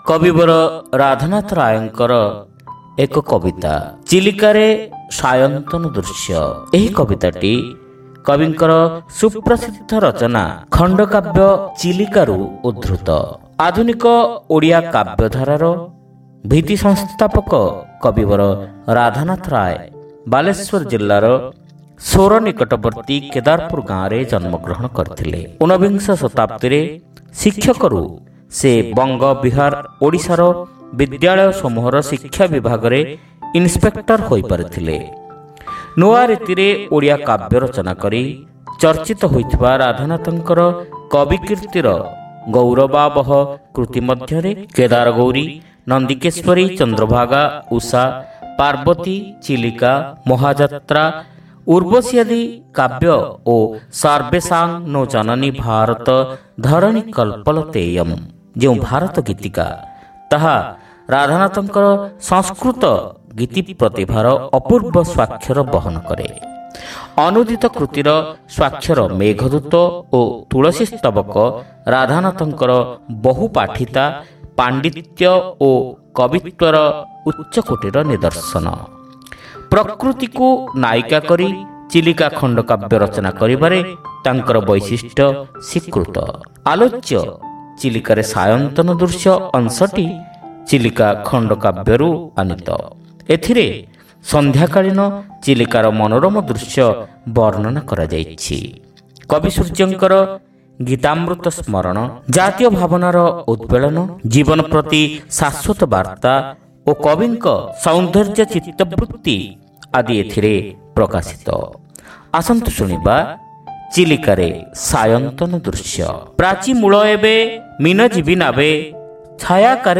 Audio Poems : Chilikare Sayantana Drushya